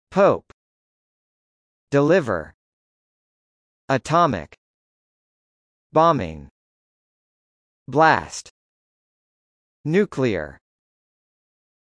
■ヒント：単語の読み上げ音声